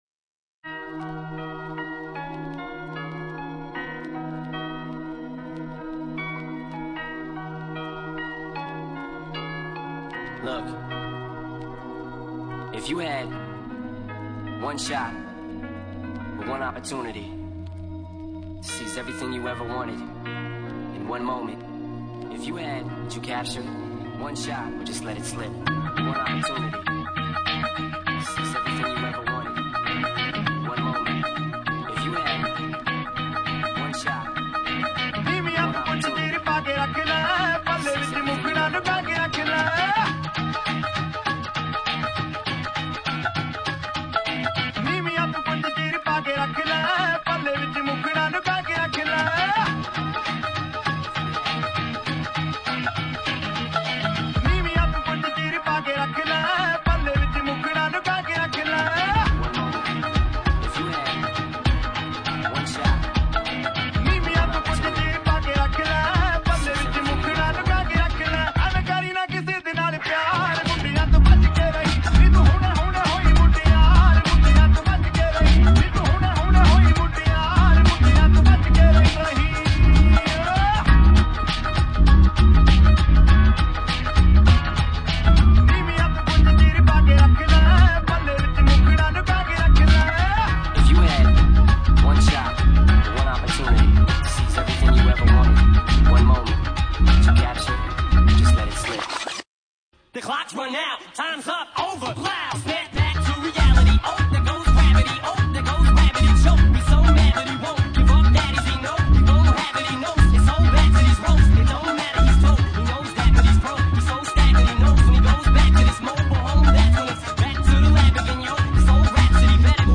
Super BaSs
Garage Bass Mix